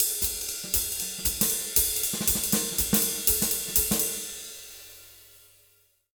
240SWING02-R.wav